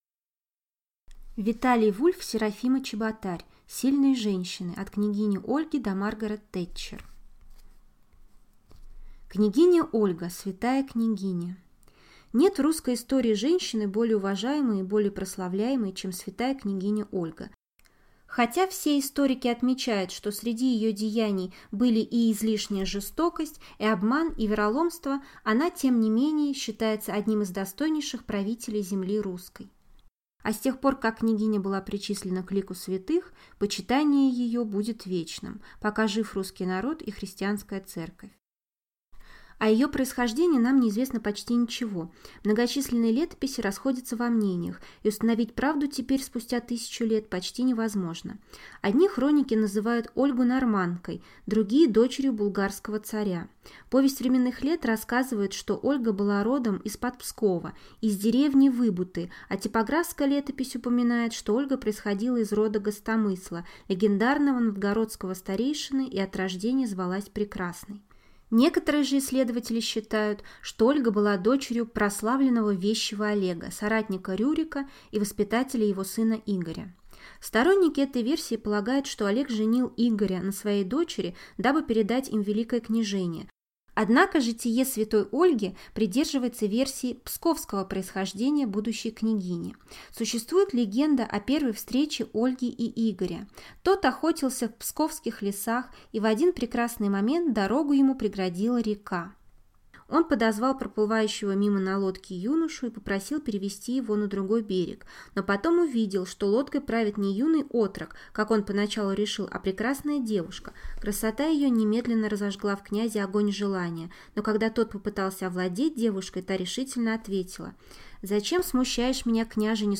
Аудиокнига Сильные женщины. От княгини Ольги до Маргарет Тэтчер | Библиотека аудиокниг